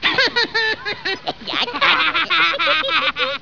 risata.wav